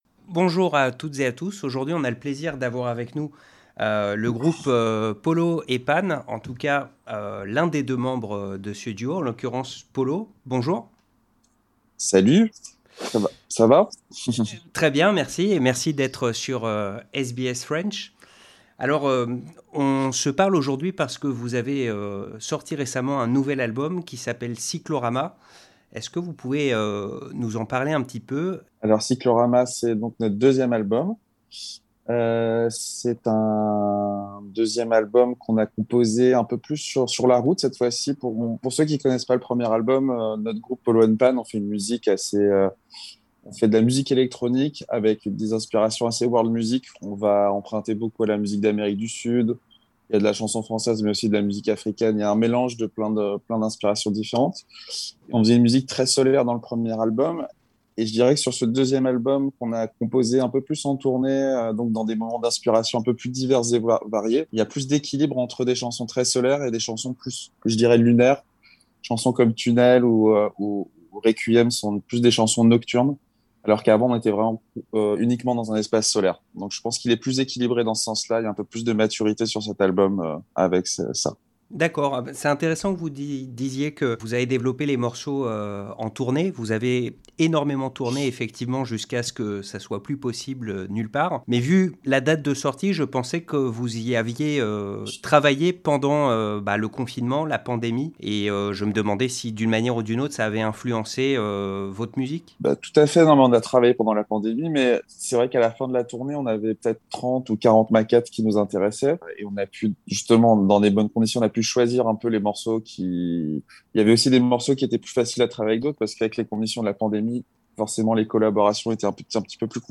french_itw_polopanchansonanikuni_ok.mp3